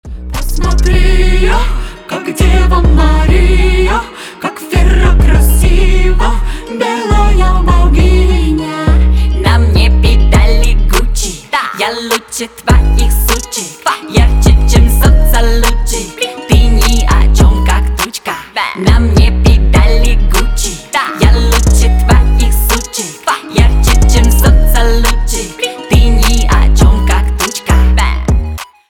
• Качество: 320 kbps, Stereo
Рэп и Хип Хоп
громкие